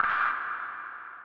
OZ - Perc 5.wav